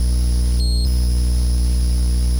静音合成器II咔嚓声和噪音 " Buzz 003
描述：来自Mute Synth 2的电子嗡嗡声/嗡嗡声。
Tag: 电子 静音-合成器-2 类似物 嗡嗡声 嘟嘟声 嗡嗡声 噪声 静音-合成器-II